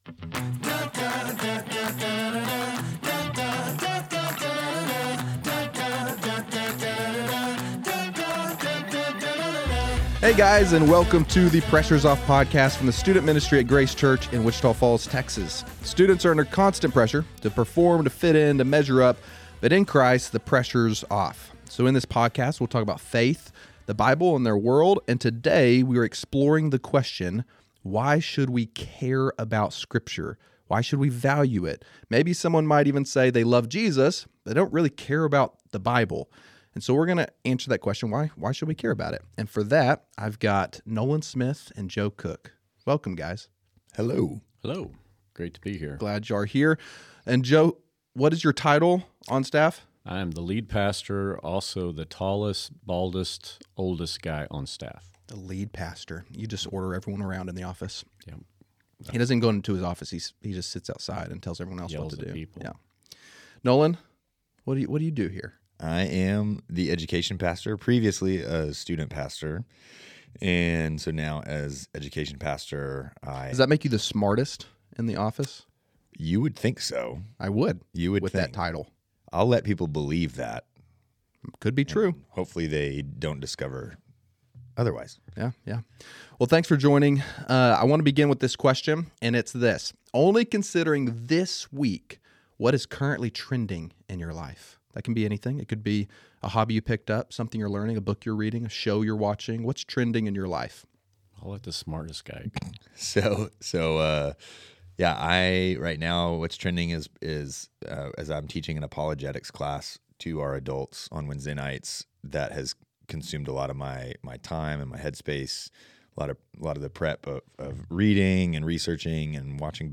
Student leaders and guests sit down to discuss life, theology, and the Bible to help students know and follow Jesus at a deeper level.